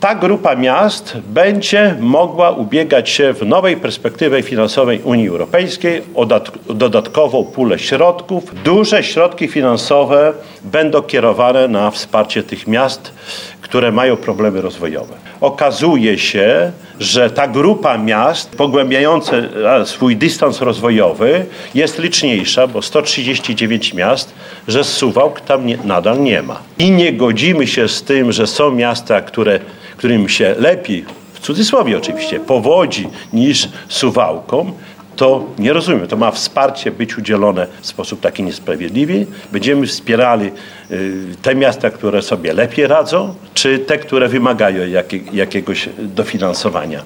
– Suwałki nie znalazły się na liście, co za tym idzie miasto będzie miało mniejsze szanse rozwojowe – tłumaczył podczas sesji Czesław Renkiewicz, prezydent miasta.